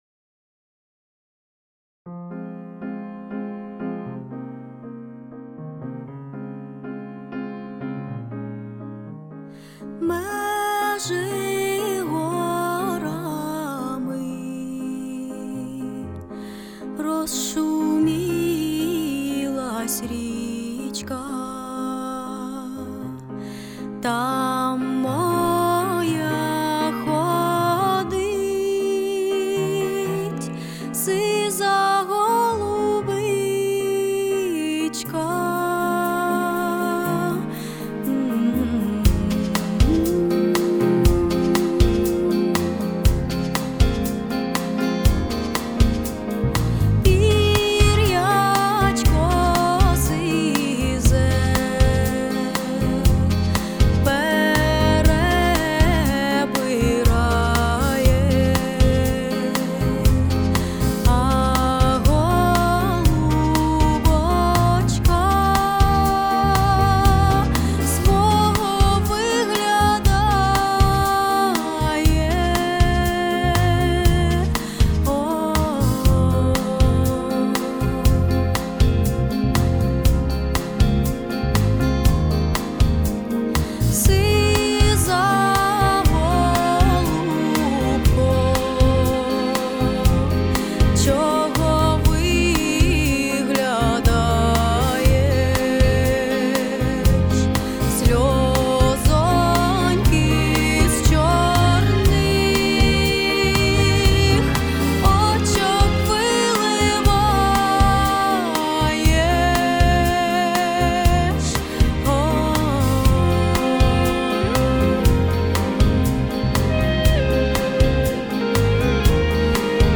вокал, джаз, Етно, поп, співачка